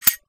GrenadePin.mp3